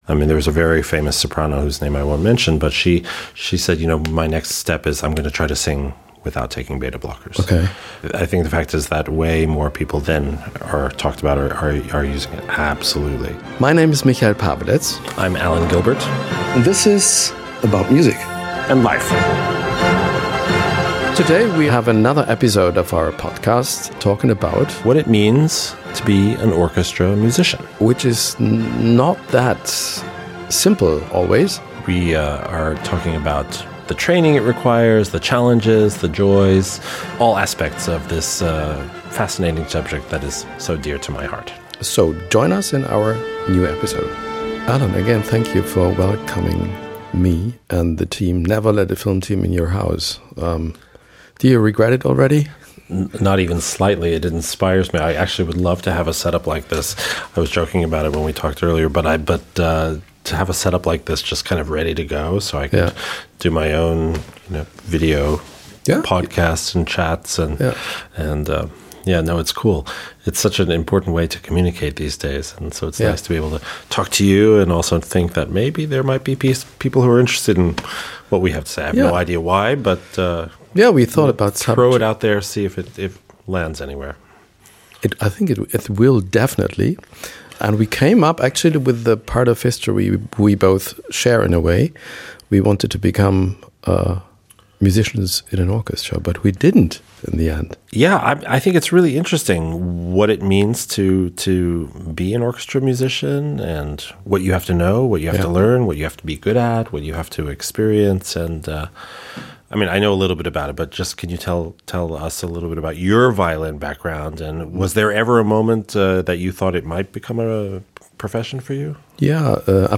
Beschreibung vor 2 Jahren Was haben Betablocker mit Auftrittsangst zu tun? Was bedeutet es, sich in ein Orchester einzufügen? In einer neuen Folge des Video-Podcasts "about music" sprechen Dirigent Alan Gilbert und Moderator Michail Paweletz (tagesschau) über ihre Erfahrungen.